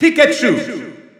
File File history File usage Pikachu_French_Announcer_SSBU.wav  (WAV audio file, length 1.2 s, 768 kbps overall) Summary [ edit ] Announcer pronouncing Pikachu .
Category:Pikachu (SSBU) Category:Announcer calls (SSBU) You cannot overwrite this file.
Pikachu_French_Announcer_SSBU.wav